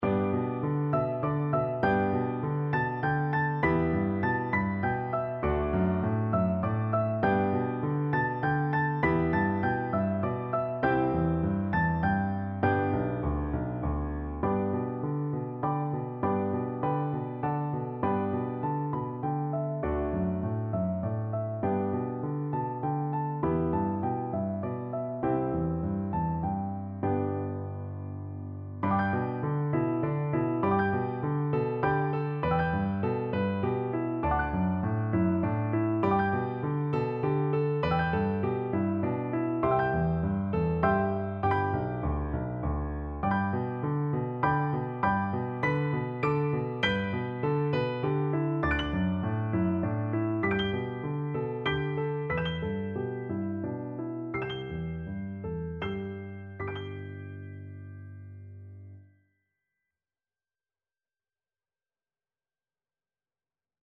Free Sheet music for Piano Four Hands (Piano Duet)
3/4 (View more 3/4 Music)